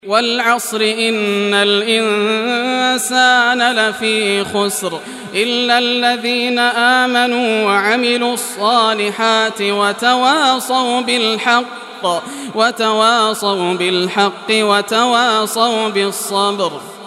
Surah Asr Rectitation by Yasser al Dosari
Surah Asr, listen or play online mp3 tilawat / recitation in Arabic in the beautiful voice of Sheikh Yasser al Dosari.
103-surah-asr.mp3